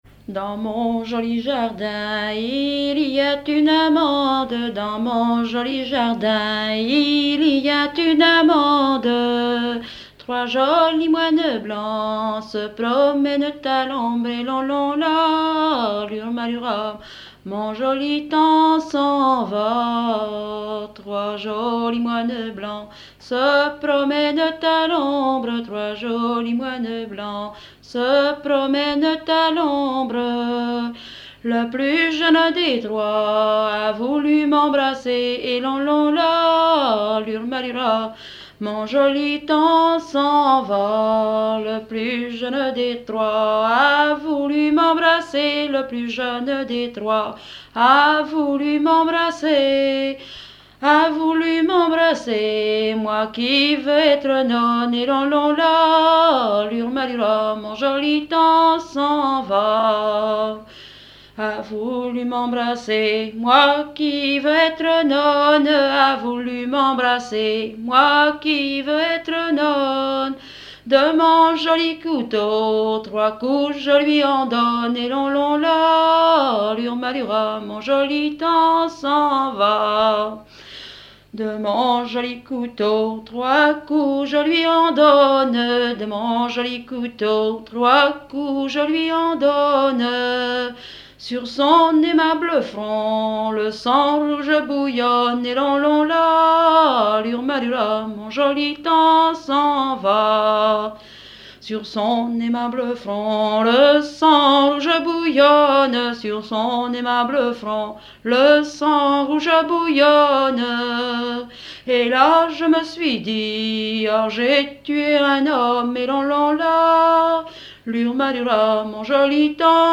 danse : ronde
Genre laisse
Pièce musicale inédite